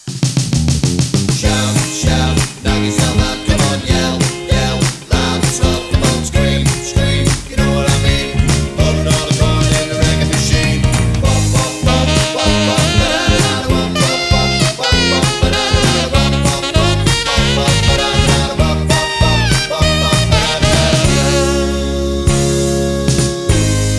No Saxophone Rock 'n' Roll 2:43 Buy £1.50